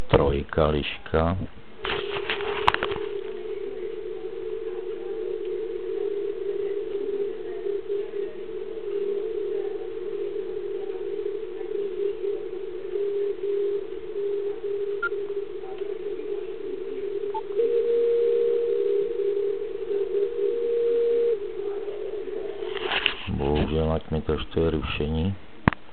Podmínky dobré, ale slyšitelnost lišek špatná.